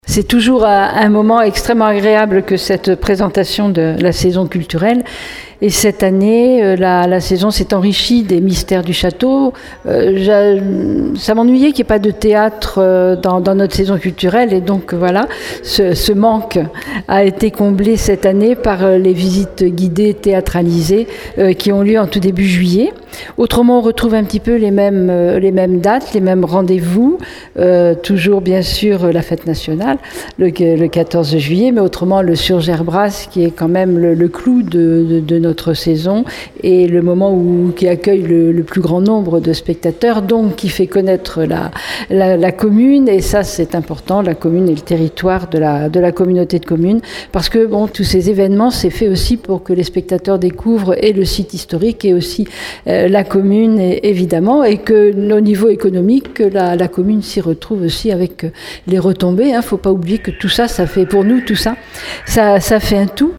La présentation en a été faite hier à la mairie. Catherine Desprez, maire de Surgères :